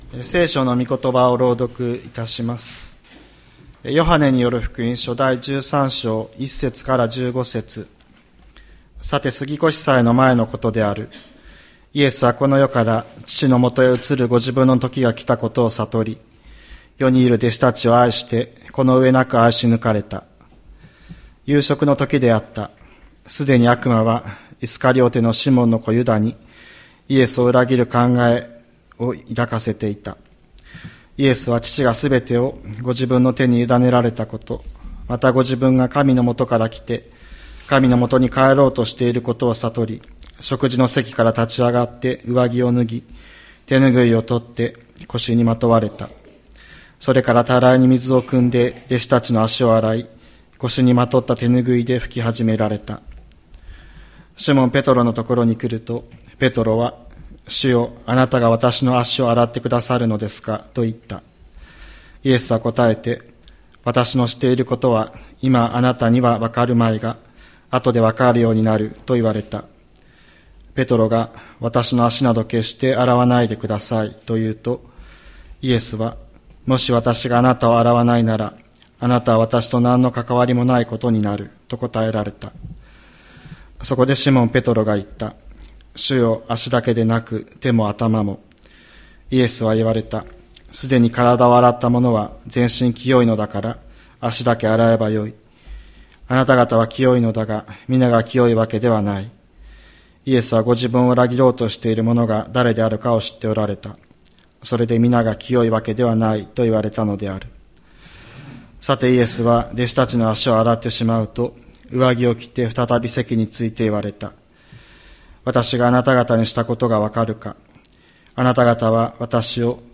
2024年11月03日朝の礼拝「愛の極み」吹田市千里山のキリスト教会
千里山教会 2024年11月03日の礼拝メッセージ。